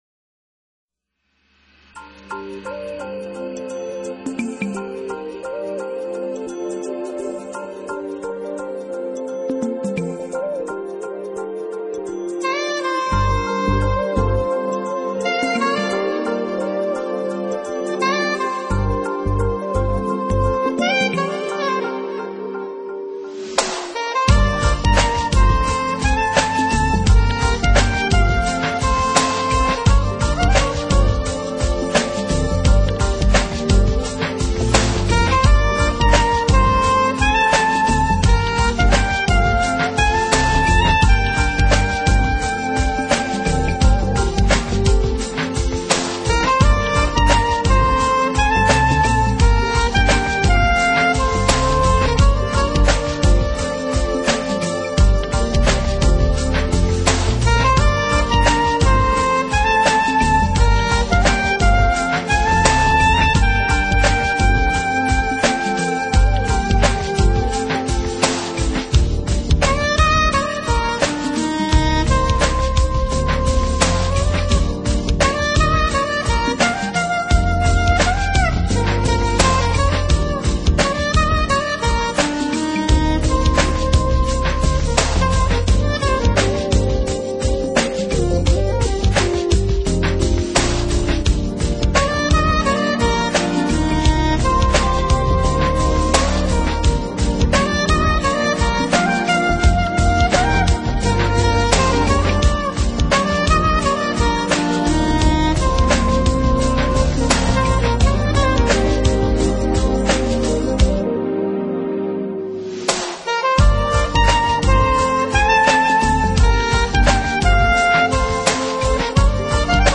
音乐类型：Jazz 爵士